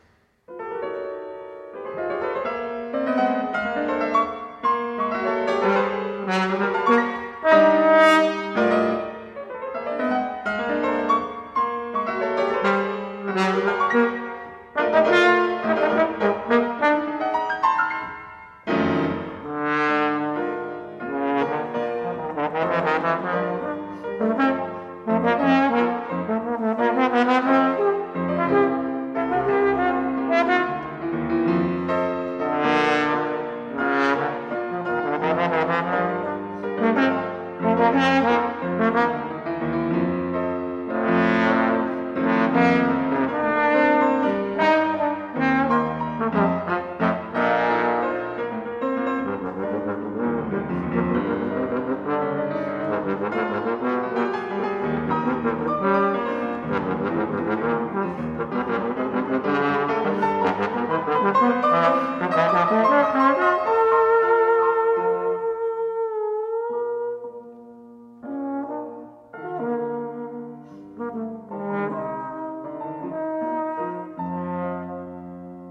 for trombone and piano